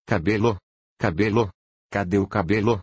Cabelo? (Twitch voice). 120 vues.
ttsmp3_OvRF09N.mp3